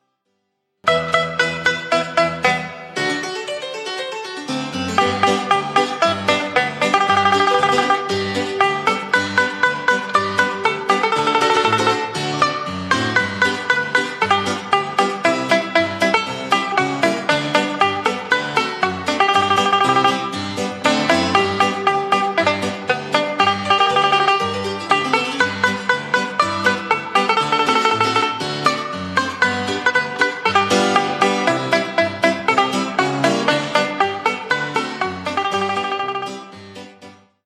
Traditional Folk Somg
This song is in 2/4 time.